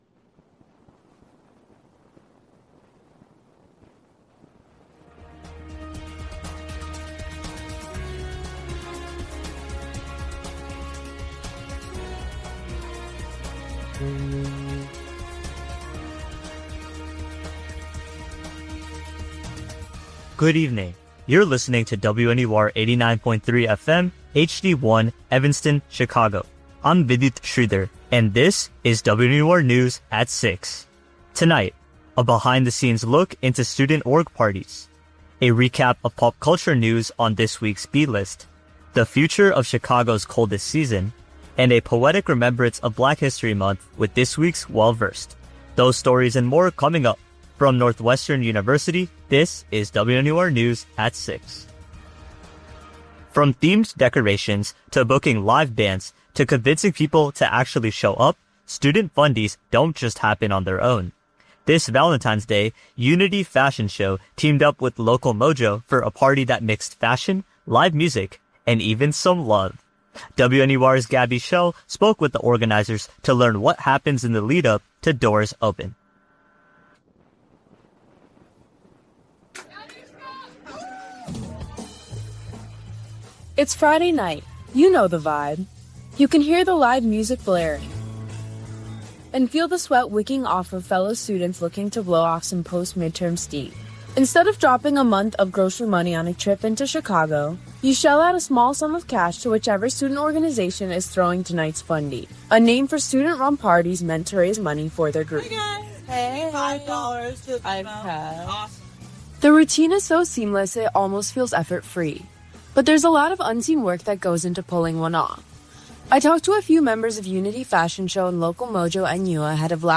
February 20, 2026: A behind the scenes look into student org parties, a recap of pop culture news on this week’s B-List, the future of Chicago’s coldest season, and a poetic remembrance of Black History Month with this week’s Well-Versed. WNUR News broadcasts live at 6 pm CST on Mondays, Wednesdays, and Fridays on WNUR 89.3 FM.